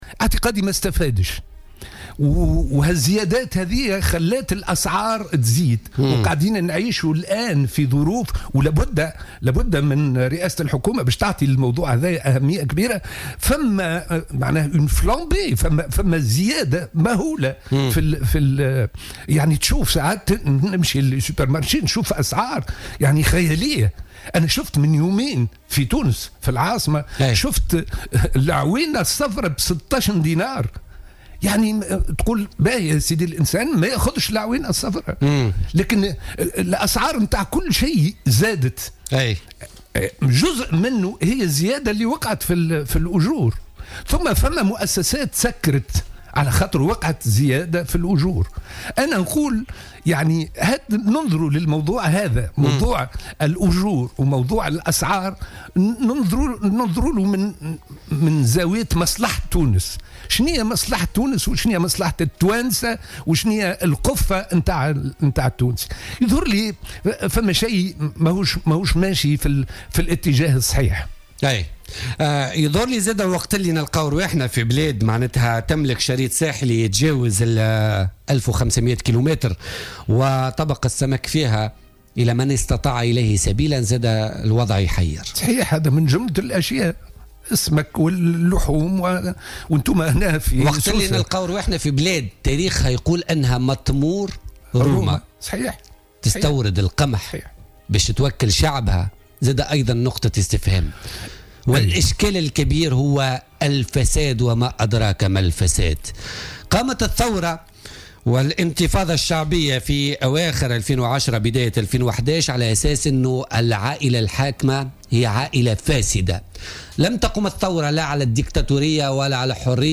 قال رئيس حزب الوطن محمد جغام ضيف بولتيكا اليوم الإثنين 1 ماي 2017 إنه كان محظوظا لأنه عاش في الساحة السياسية منذ عهد بورقيبة.